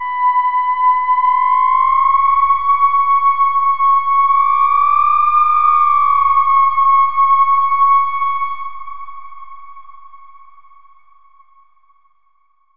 Theremin_Swoop_02.wav